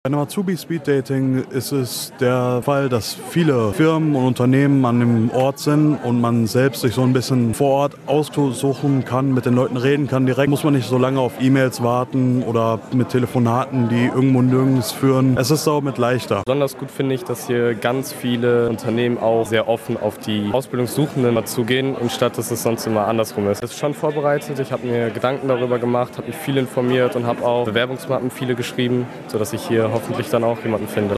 azubi-speeddating-o-toene.mp3